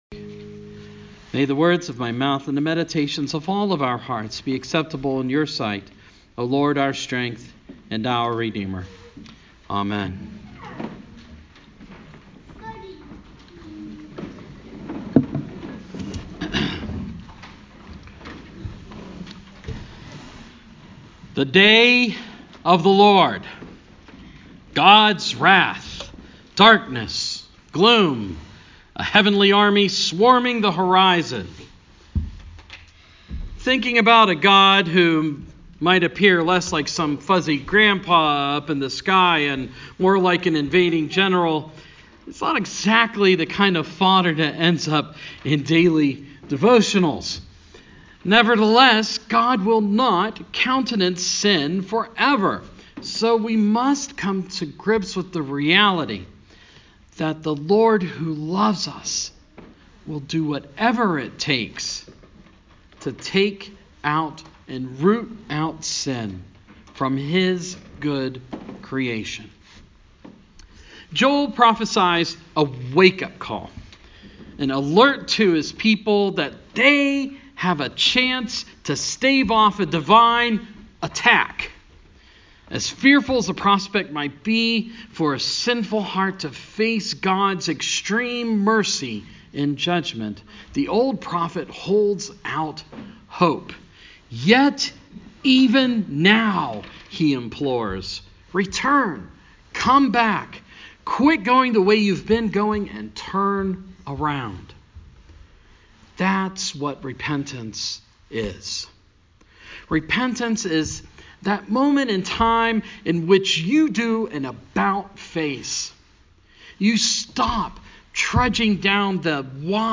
Ash Wednesday